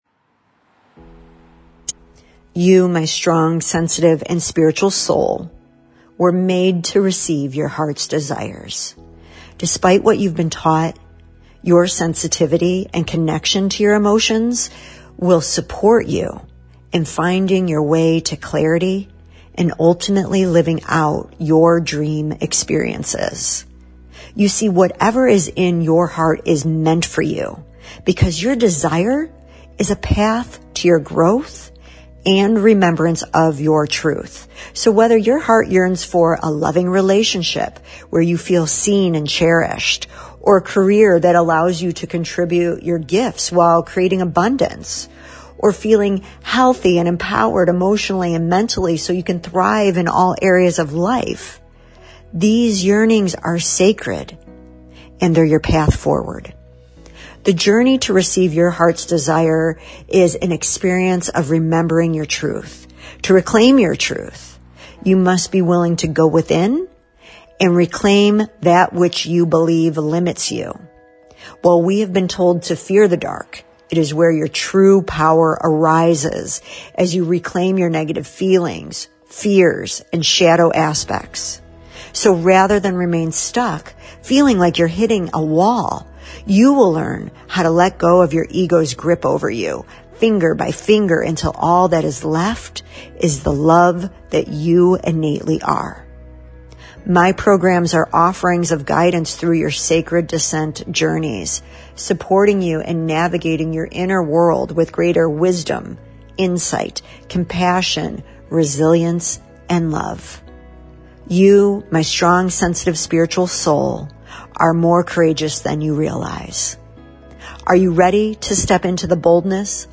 work-with-me-with-bg-sound.mp3